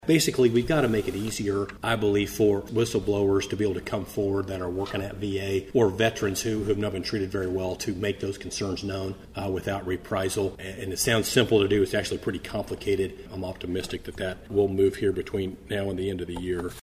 1st District Congressman Tracey Mann was the featured guest at the Manhattan Area Chamber of Commerce Military Relations Committee Luncheon, held Wednesday at the Manhattan Conference Center.